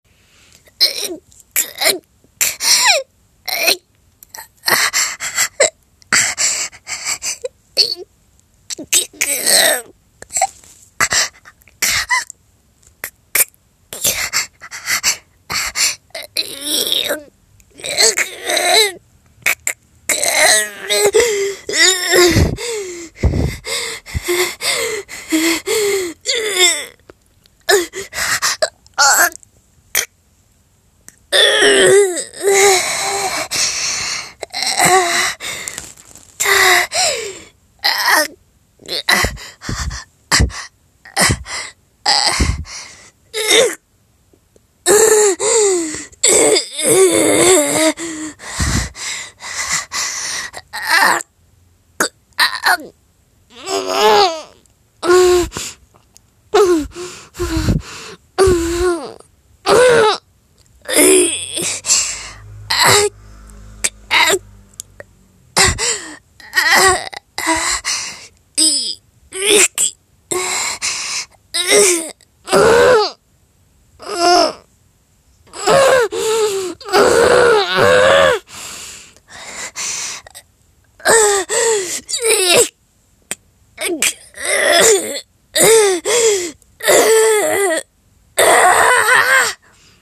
全力で苦しむ声をやってみた。